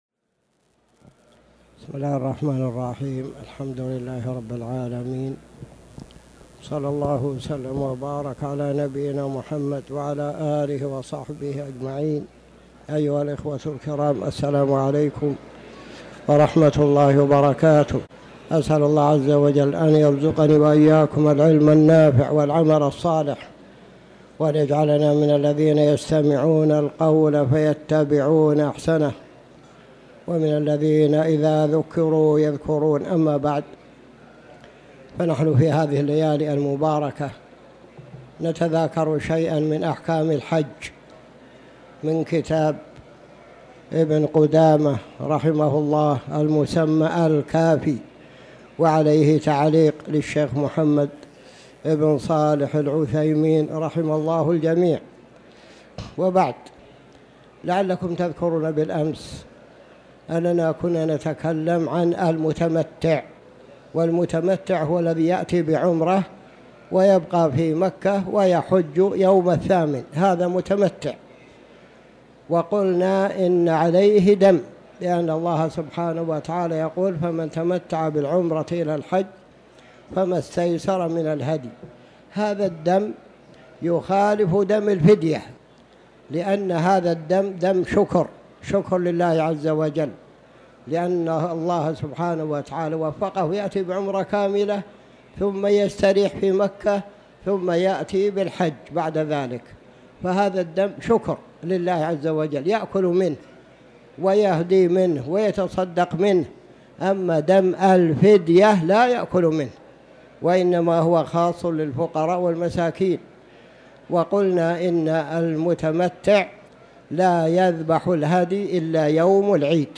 تاريخ النشر ٢٨ ذو القعدة ١٤٤٠ هـ المكان: المسجد الحرام الشيخ